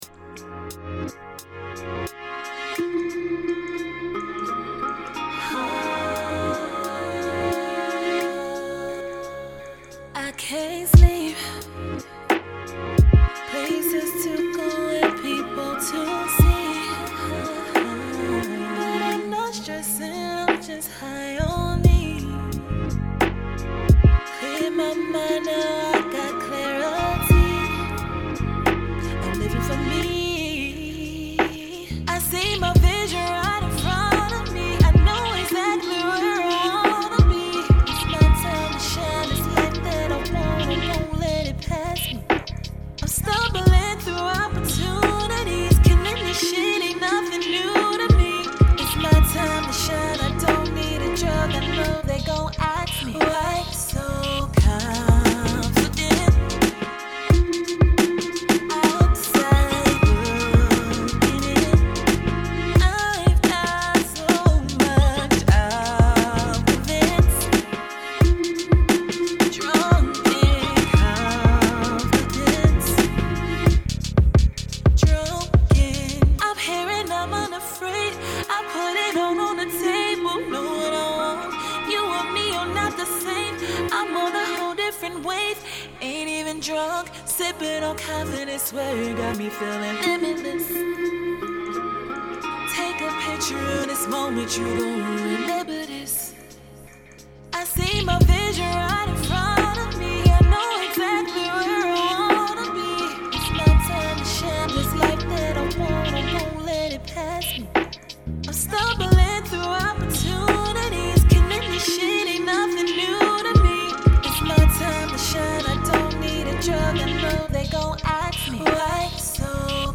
known for her soulful voice and captivating performances.